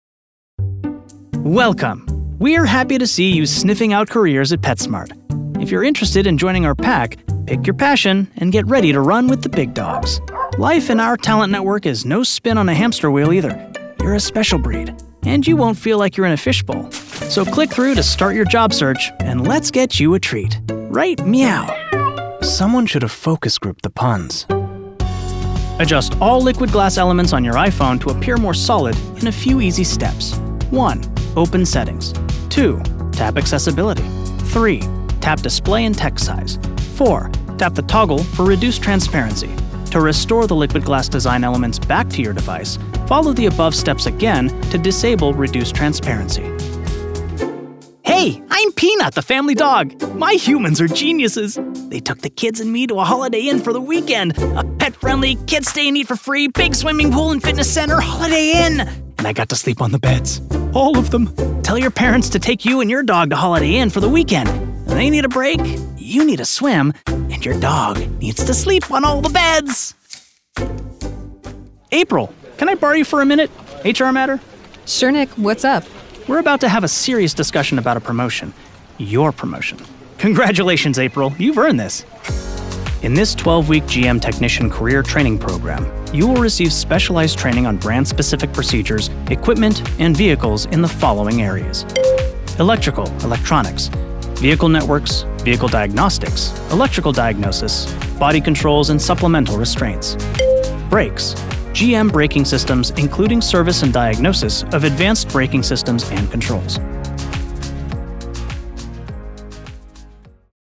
Never any Artificial Voices used, unlike other sites.
Yng Adult (18-29) | Adult (30-50)